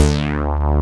SYN_StBas D4.wav